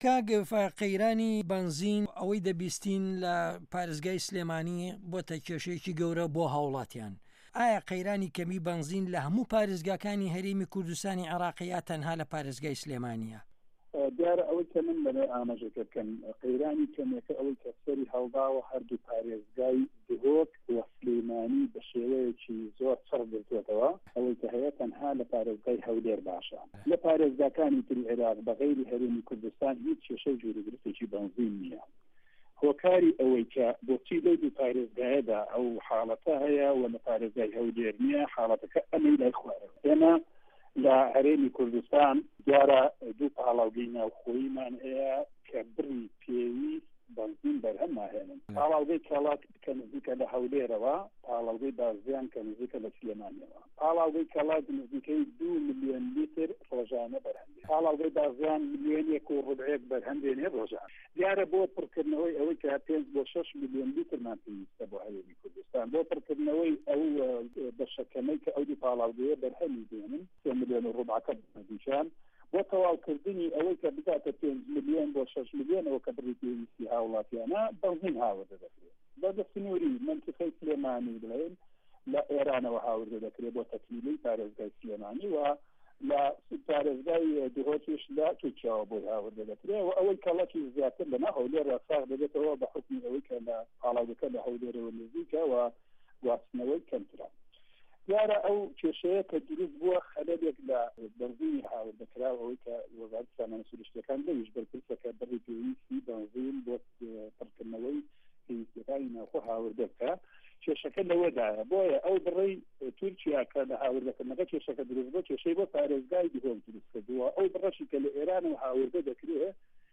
وتوێژ له‌گه‌ڵ فایه‌ق موسته‌فا ره‌سول